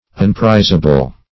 Search Result for " unprizable" : The Collaborative International Dictionary of English v.0.48: Unprizable \Un*priz"a*ble\, a. 1.